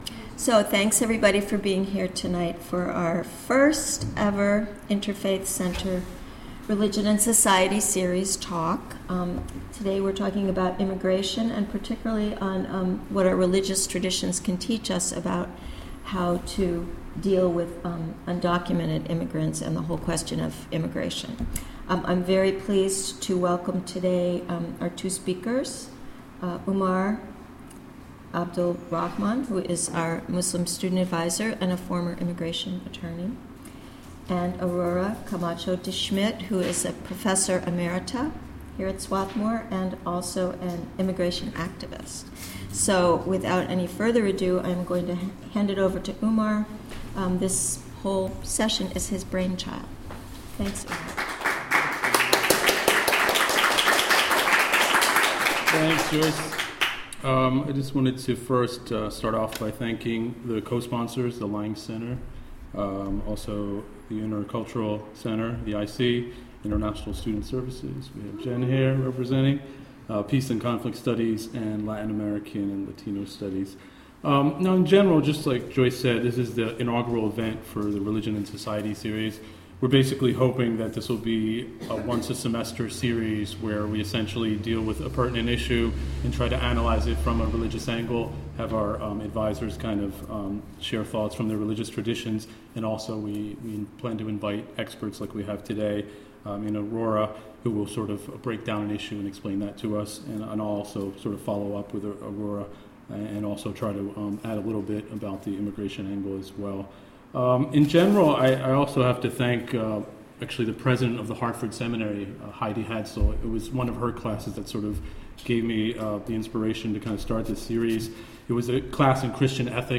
This discussion, sponsored by the Interfaith Center